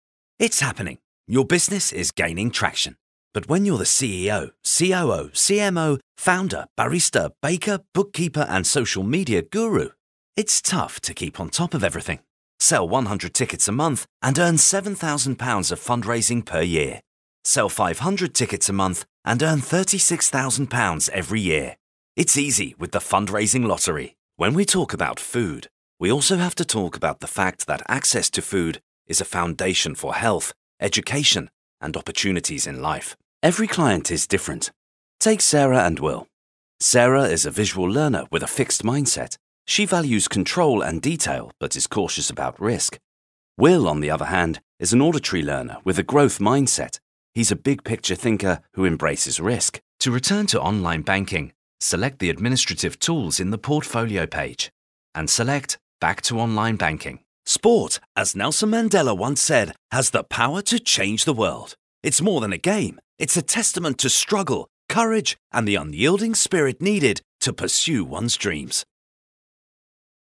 Inglés (Reino Unido)
Vídeos corporativos
EV RE-20, interfaz UAD
BarítonoBajoProfundoBajo
CálidoConversacionalCreíbleVersátilDiversiónAmistosoCon carácter